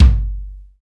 KIK XR.BDR08.wav